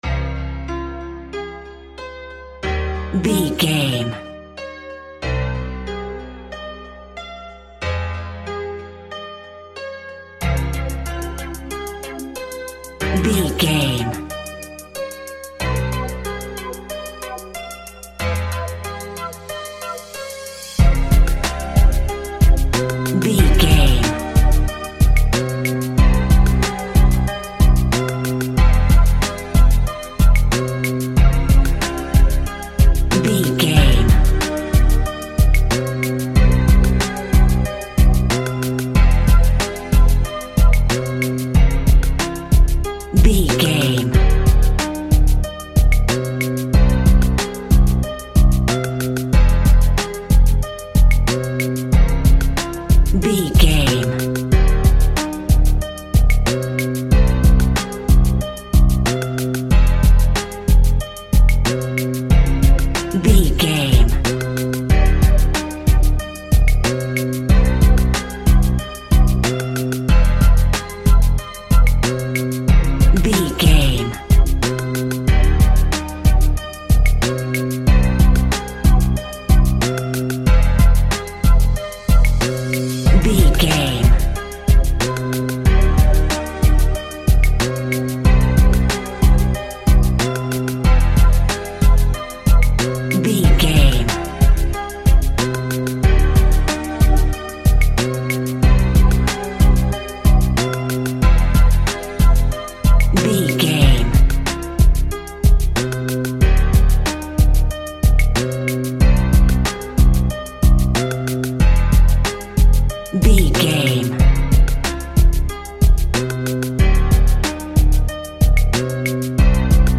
Back to the 90s Hip Hop Sound.
Aeolian/Minor
hip hop instrumentals
chilled
laid back
groove
hip hop drums
hip hop synths
piano
hip hop pads